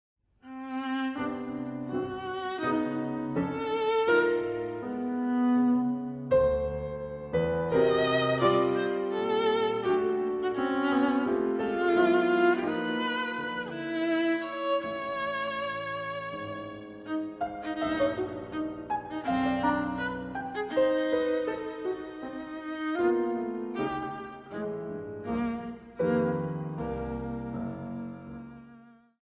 Sonata for viola and piano op. 117